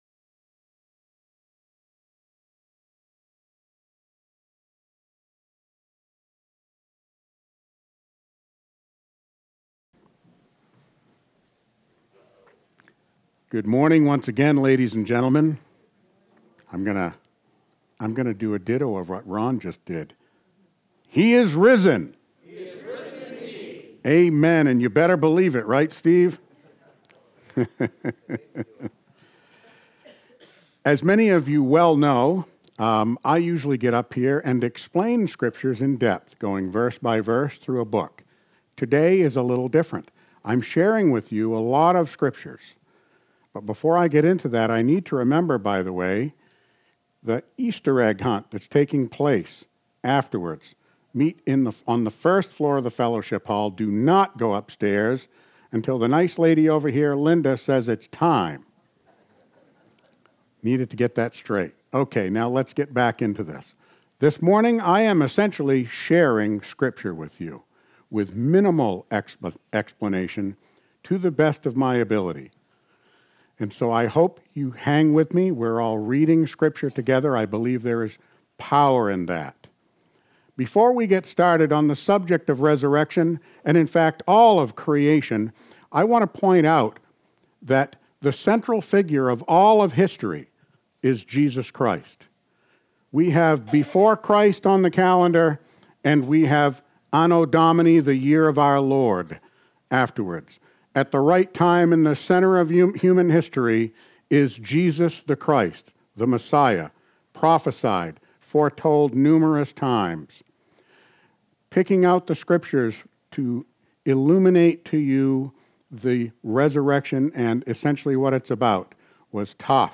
Sunday, March 31, 2024 Worship Service: Resurrection Sunday